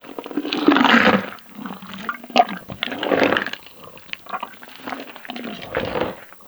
MONSTER_Slime_01_mono.wav